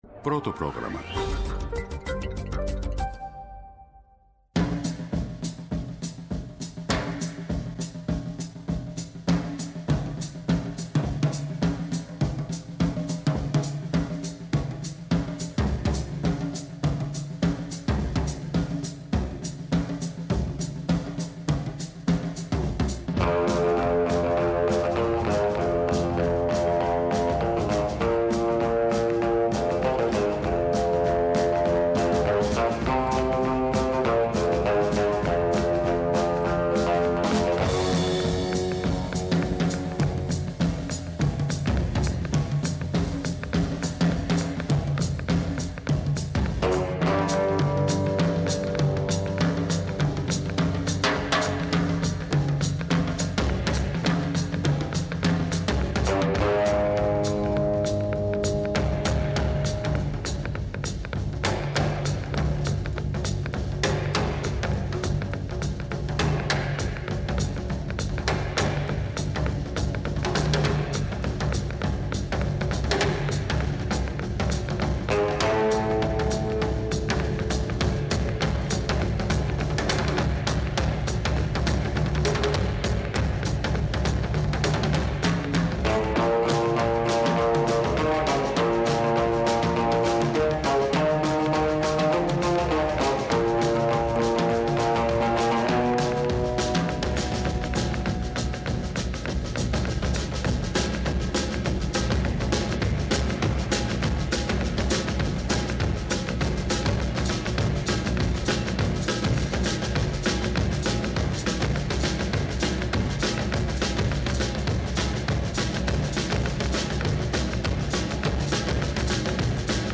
ΜΟΥΣΙΚΗ